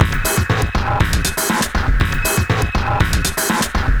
On The Move (Drums) 120 BPM.wav